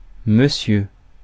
Ääntäminen
US : IPA : /ˈmæs.tɚ/